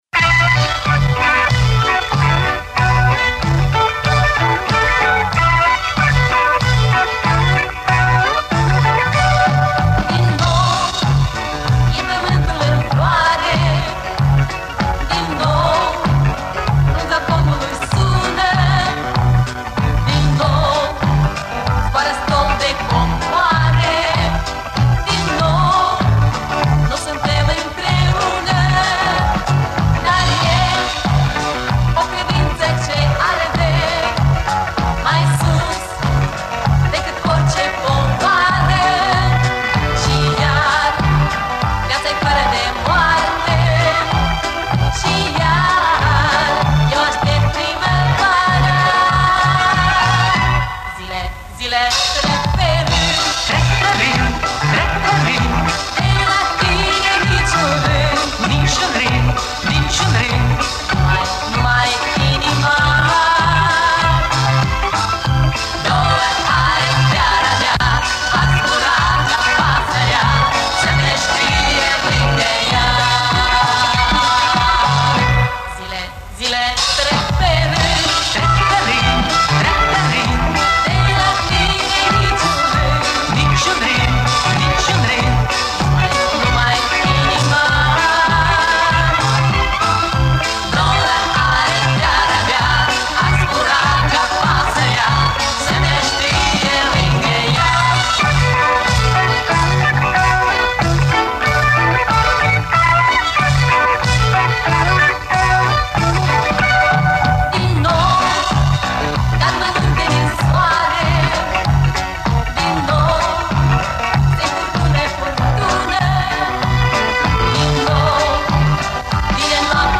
немножко вытащил голос